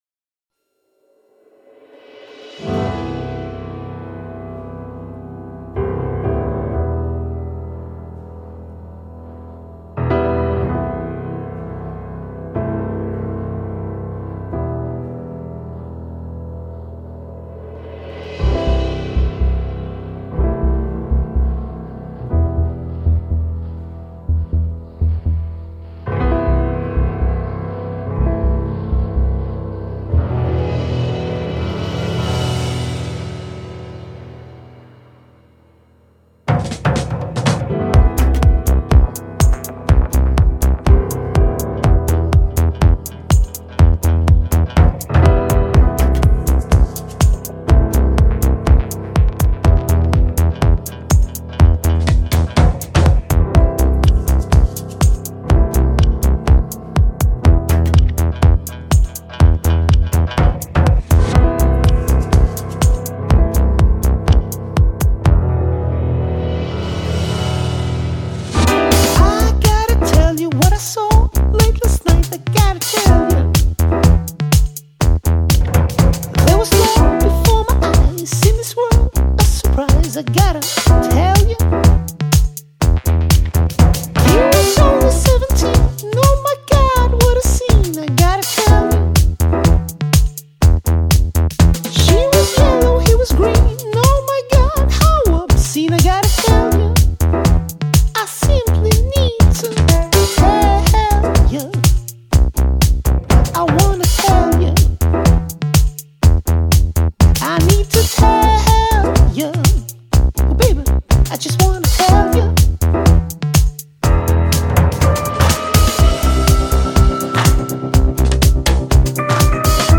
Organic, surprising and catchy as fuck!
Category: Up Tempo